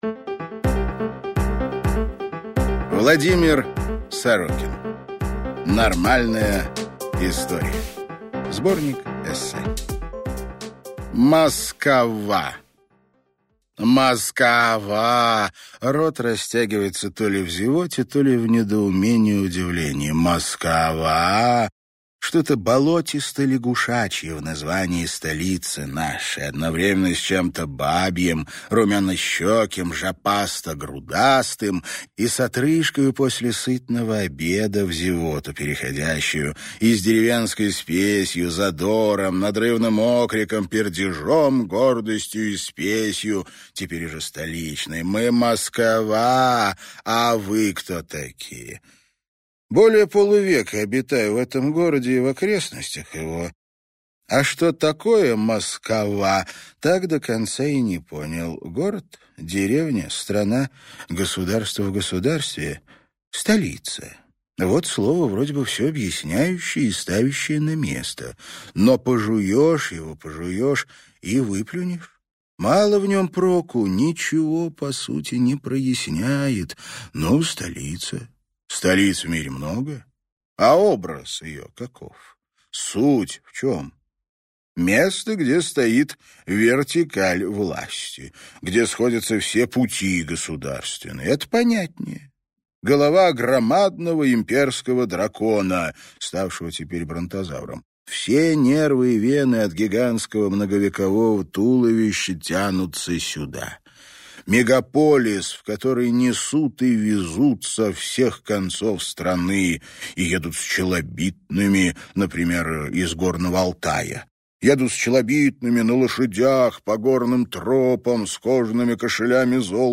Aудиокнига Нормальная история